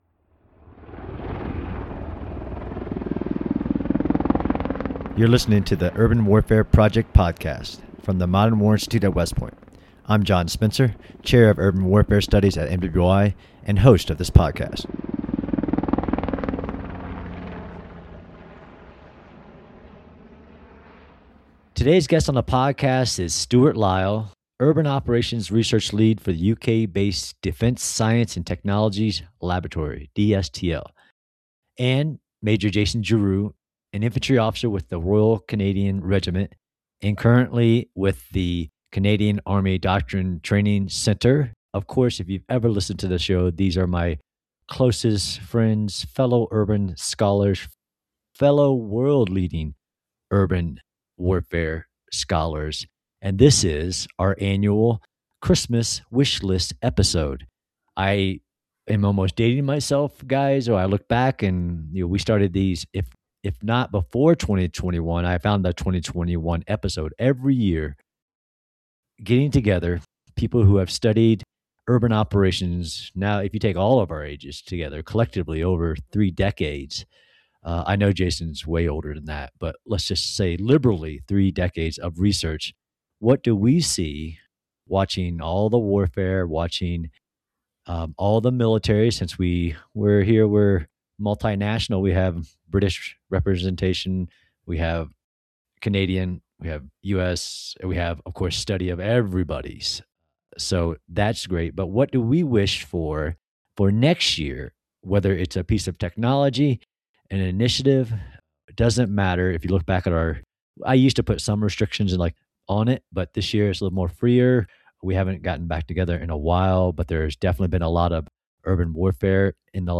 What items should be on a military force's urban warfare holiday wish list? To do so, he is joined as always by two urban warfare scholars to discuss the unique weapons and tools, programs and initiatives, capabilities and ideas they would wish into existence for any military force pre